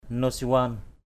/no-si-wan/ (d.) Nouchirvan = Nouchirvan (Khosroès).